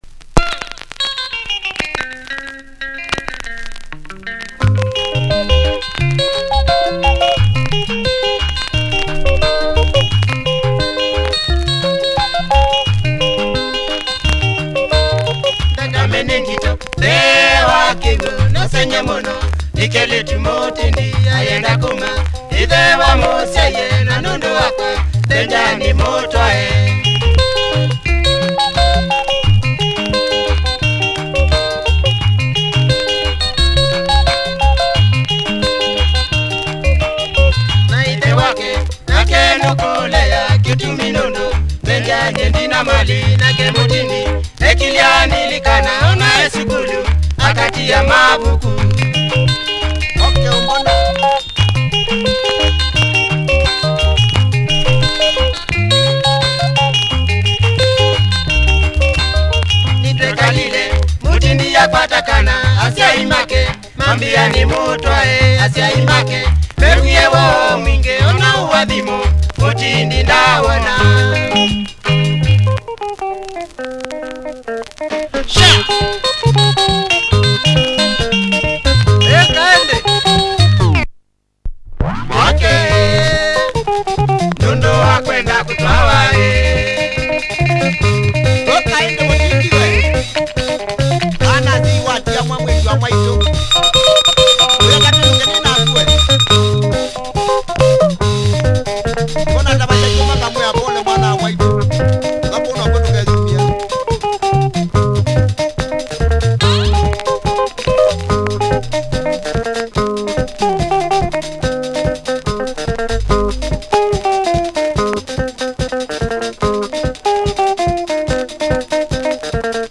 Uptempo Catchy Kamba benga, furious breakdown!